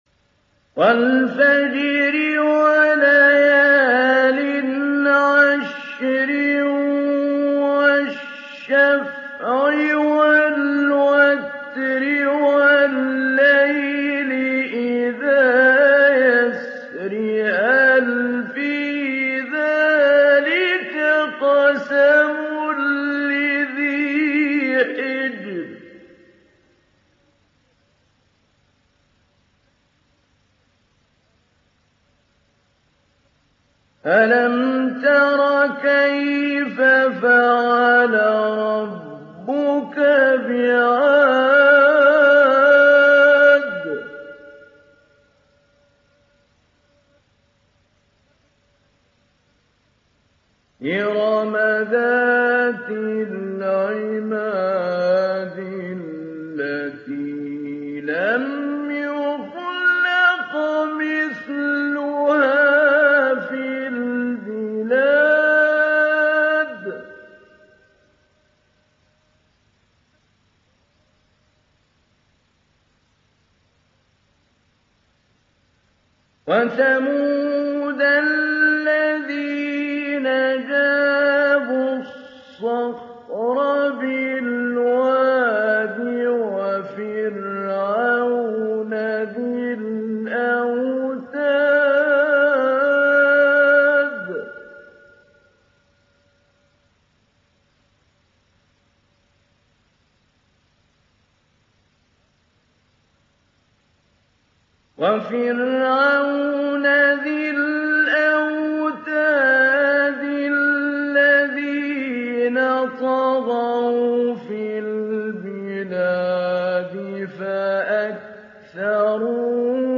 Télécharger Sourate Al Fajr Mahmoud Ali Albanna Mujawwad